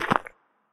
step-2.ogg.mp3